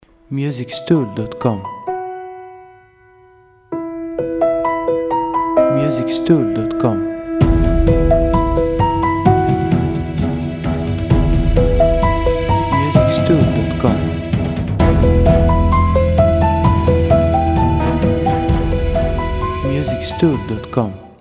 • Type : Instrumental
• Bpm : Adagio
• Genre : Ambient / Cinematic / Action / Battle Soundtrack